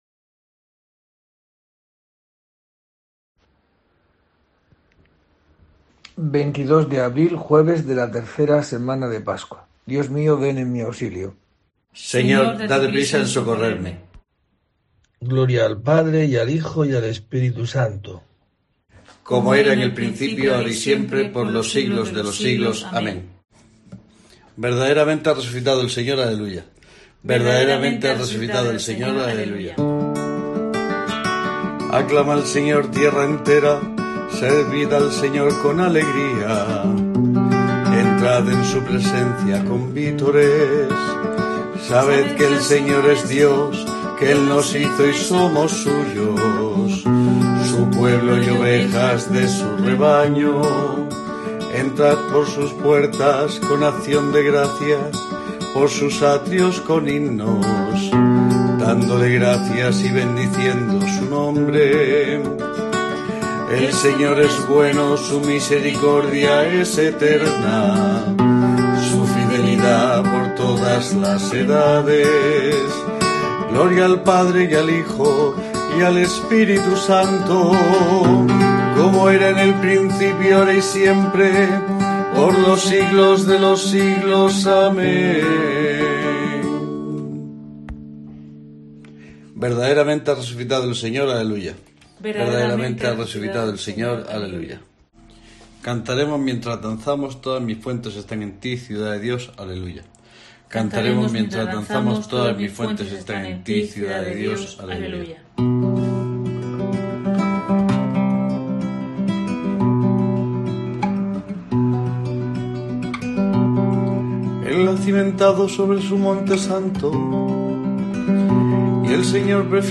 22 de abril: COPE te trae el rezo diario de los Laudes para acompañarte